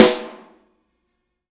R_B Snare 03 - Close.wav